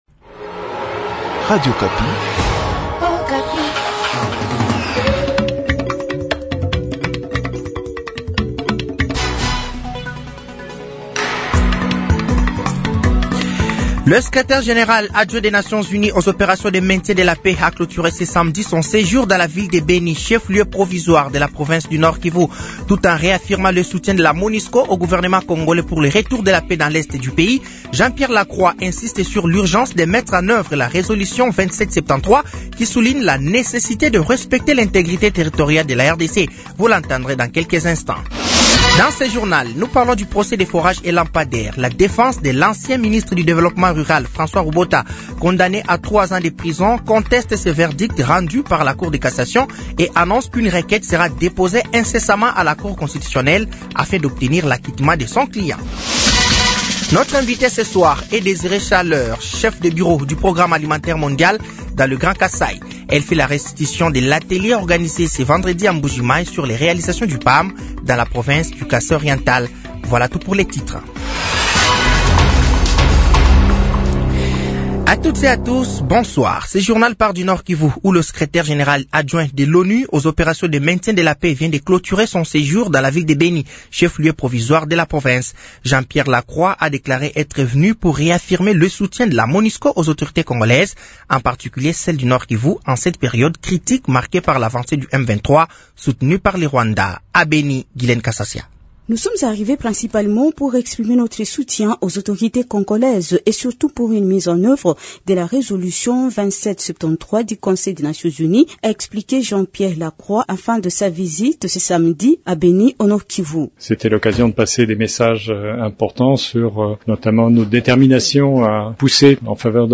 Journal francais de 18h de ce samedi 01 mars 2025